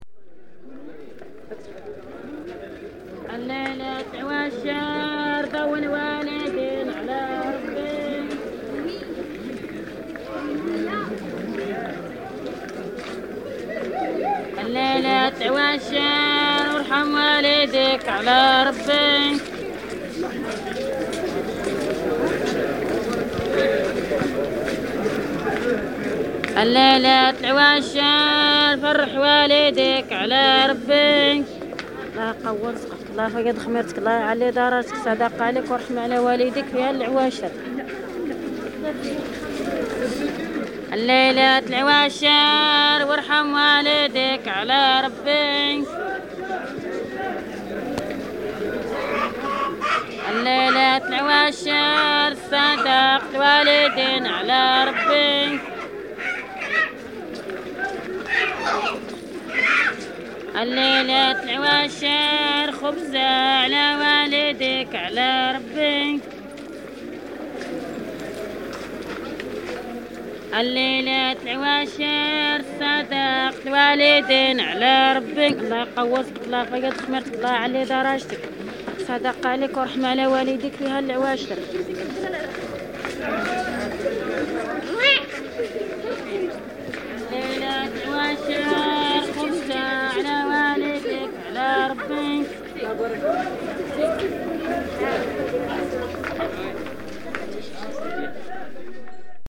Ait Haddidu beggar singing for bread
From the sound collections of the Pitt Rivers Museum, University of Oxford, being from a collection of reel-to-reel tape recordings of Berber (Ait Haddidu) music and soundscapes made by members of the Oxford University Expedition to the Atlas Mountains of Southern Morocco in 1961.